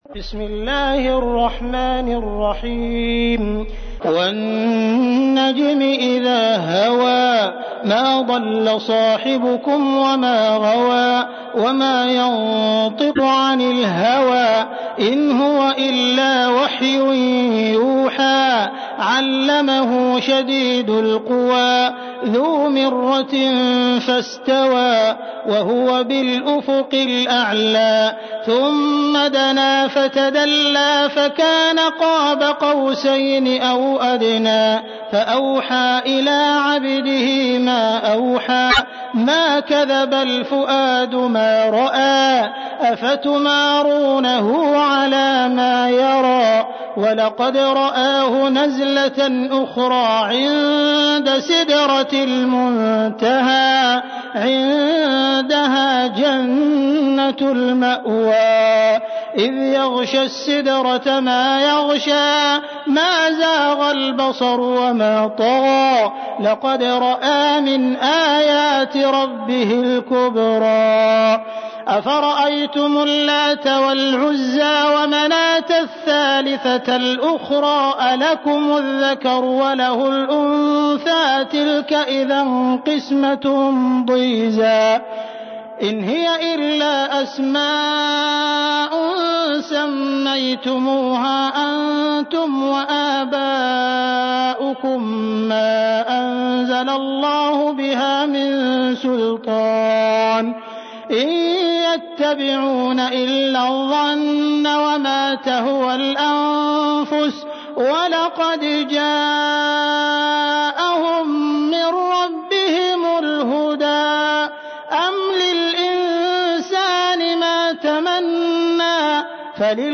تحميل : 53. سورة النجم / القارئ عبد الرحمن السديس / القرآن الكريم / موقع يا حسين